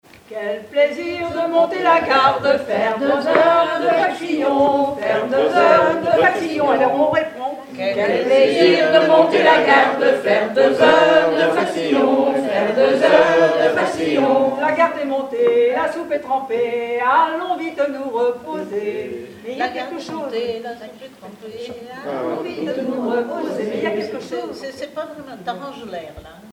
Mémoires et Patrimoines vivants - RaddO est une base de données d'archives iconographiques et sonores.
Rondes à baisers et à mariages fictifs
danse : ronde à embrasser
Chansons, formulettes enfantines
Pièce musicale inédite